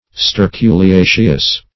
Sterculiaceous \Ster*cu`li*a"ceous\, a. [NL.